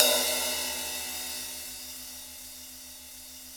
J   CRASH 3.wav